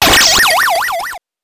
SFX
8 bits Elements